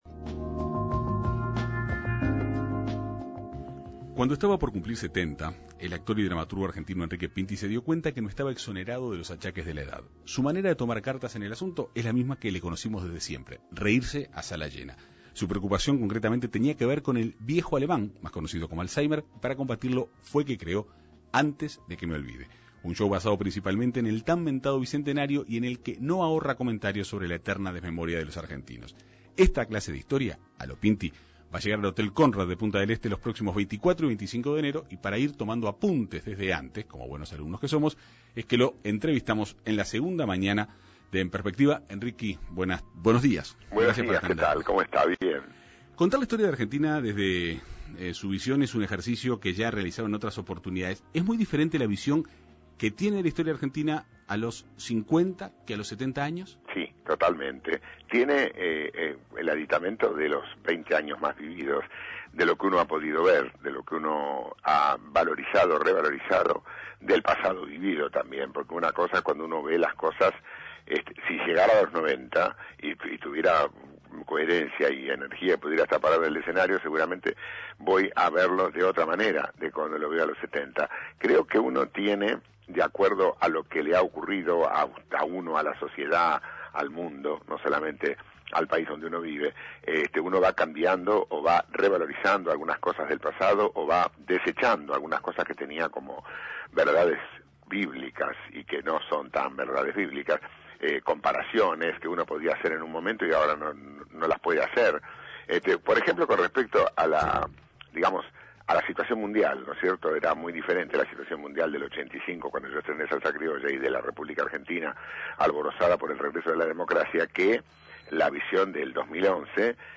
Para combatirlo creó "Antes de que me olvide", un show basado principalmente en el bicentenario y en el que no ahorra comentarios sobre la eterna desmemoria de los argentinos. La Segunda Mañana de En Perspectiva entrevistó a Pinti para saber más sobre su historia, la cual llegará al Conrad de Punta del Este los próximos 24 y 25 de enero.